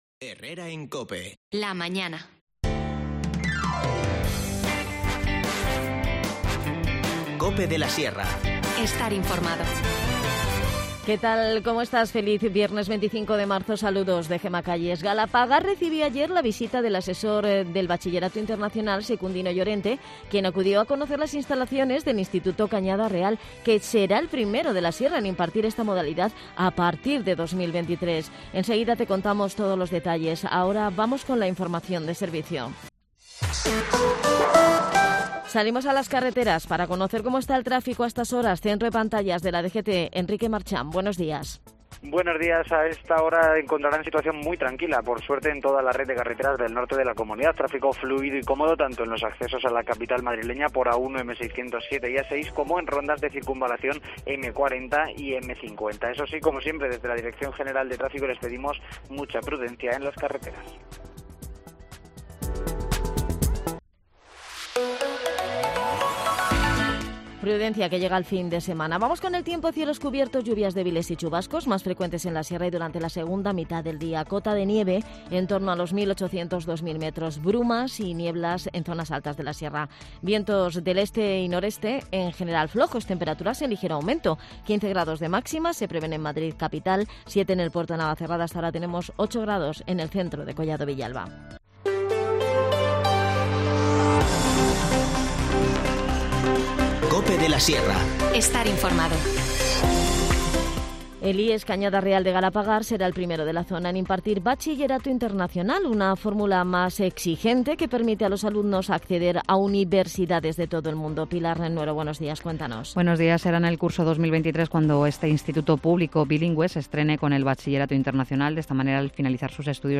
En el tiempo de Deportes en Herrera en COPE, su alcalde, Juan Rodríguez, nos detalla esta y otras pruebas que se van al llevar a cabo en la localidad.